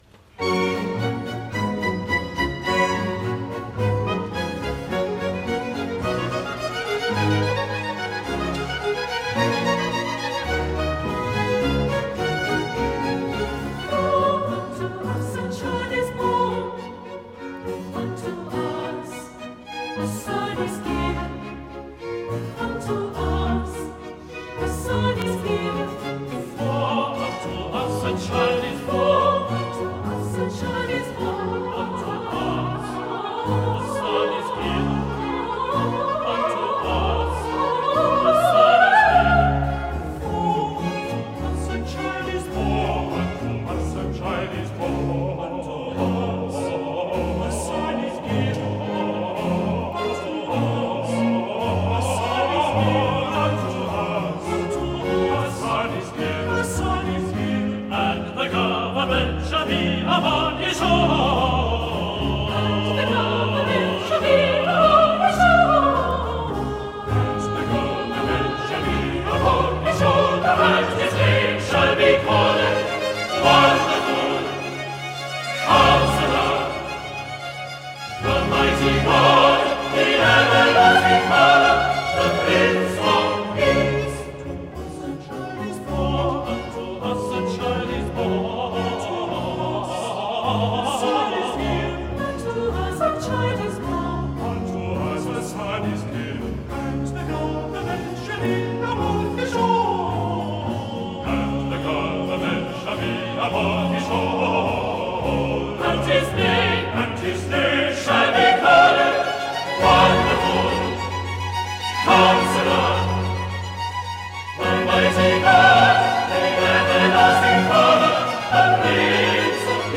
I finalment, de tots els fragments corals possibles, tots ells “reclinatoriables”, he escollit el revitalitzant “For unto us a Child is born”
MUSIKFEST ERZGEBIRGE – Eröffnungskonzert
THE SIXTEEN
Direcció: Harry Christophers
Sankt Marienkirche Marienberg